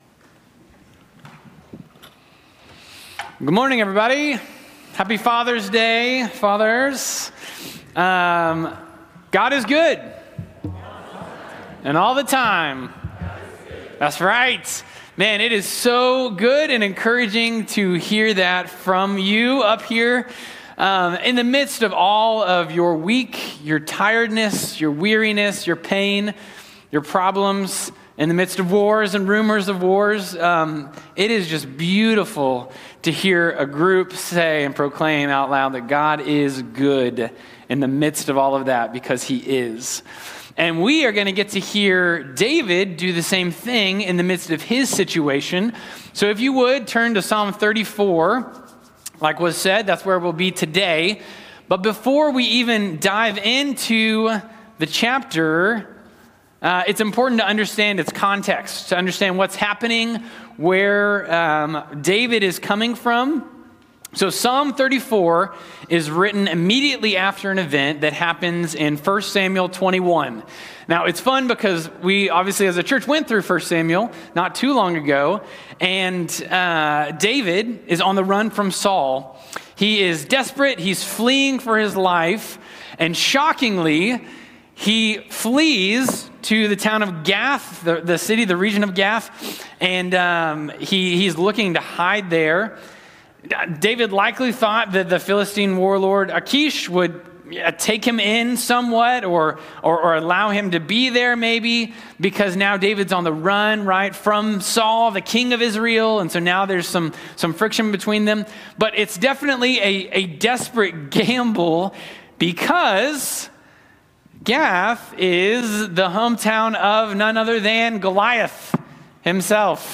by South Spring Media | Jun 15, 2025 | 2025 Sermons, 34, Psalms, Psalms Series, Scripture, Series, Sermons | 0 comments